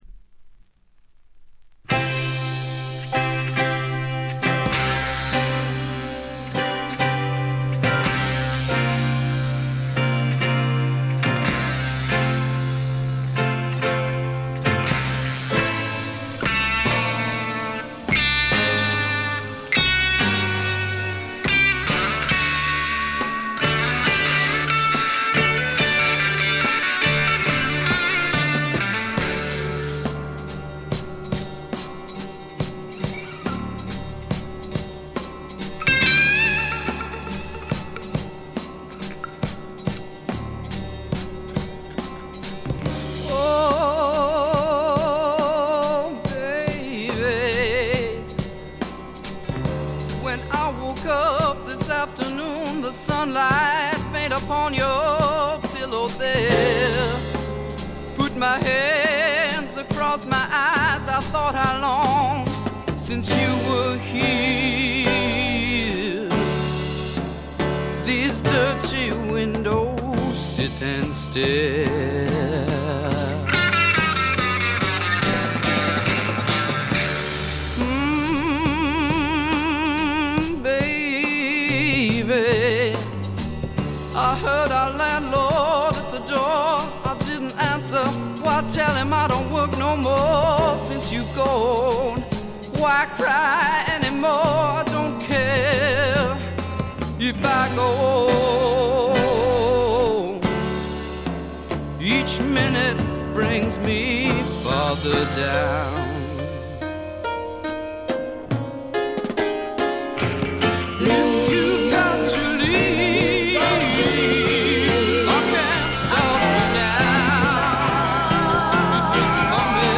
ごくわずかなノイズ感のみ。
試聴曲は現品からの取り込み音源です。